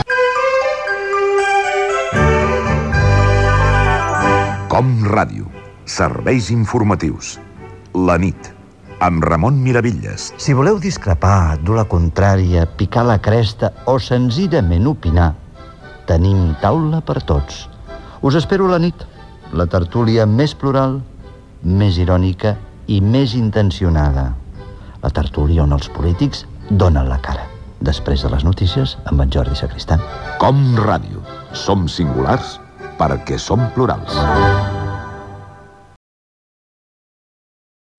1b0ed611245c70d83bd41ae318af4e6ede536bb9.mp3 Títol COM Ràdio - La nit Emissora COM Ràdio Barcelona Titularitat Pública nacional Nom programa La nit Descripció Promoció de "La nit". Gènere radiofònic Publicitat